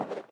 paw_earth3.ogg